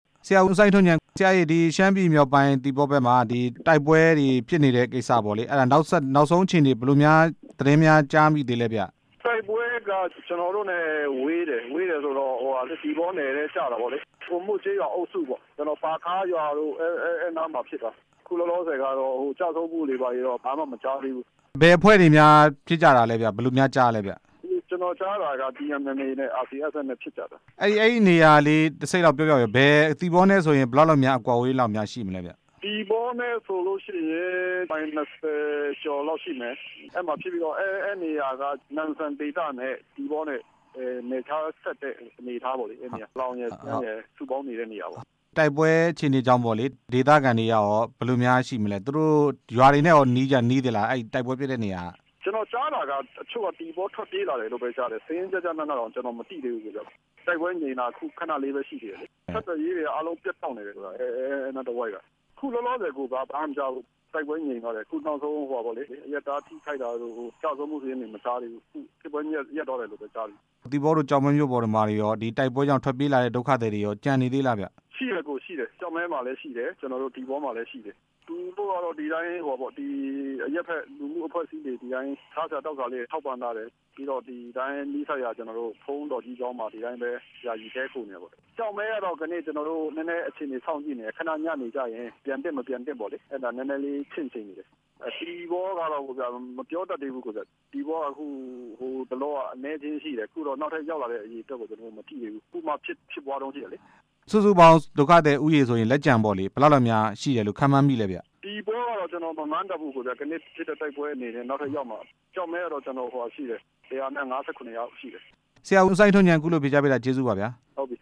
ရှမ်းတိုင်းရင်းသားများ ဒီမိုကရေစီ အဖွဲ့ချုပ်ပါတီက ကျောက်မဲမြို့နယ် ပြည်နယ်လွှတ်တော်ကိုယ်စားလှယ် ဦးစိုင်းထွန်းဉာဏ် ကို RFA သတင်းထောက်